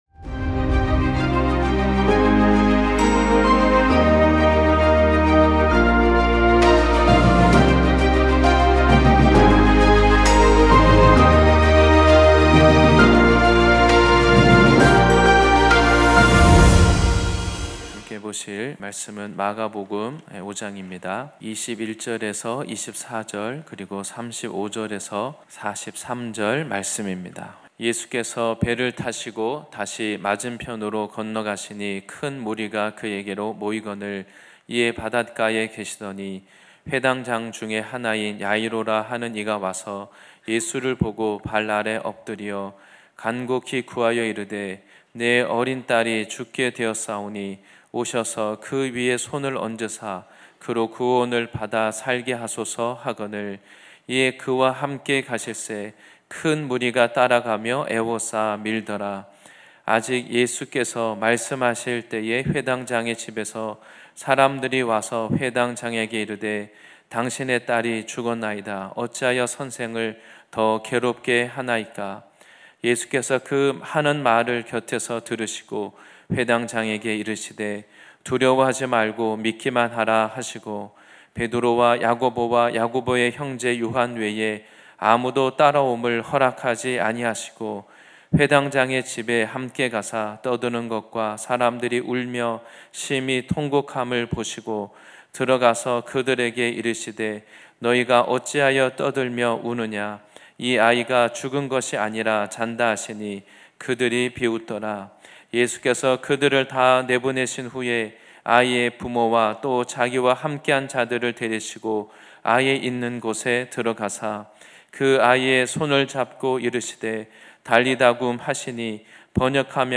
주일예배말씀